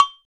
Index of /90_sSampleCDs/NorthStar - Global Instruments VOL-2/PRC_Cowbells/PRC_Cowbells